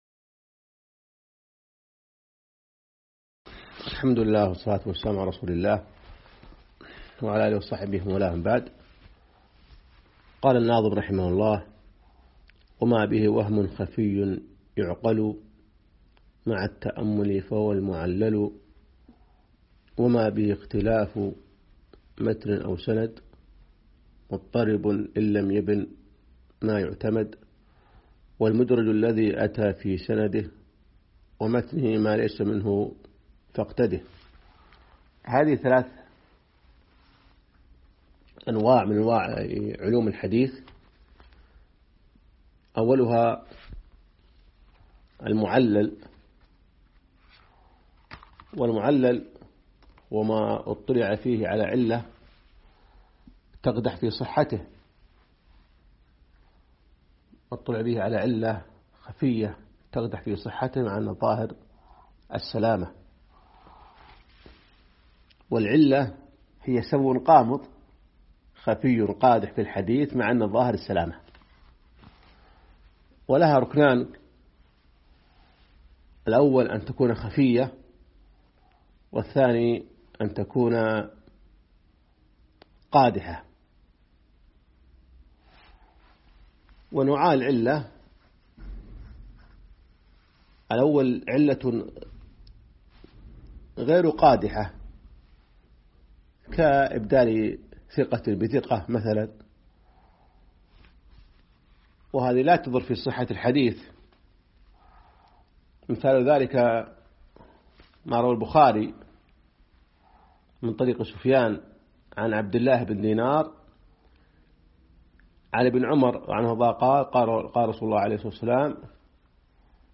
الدرس 11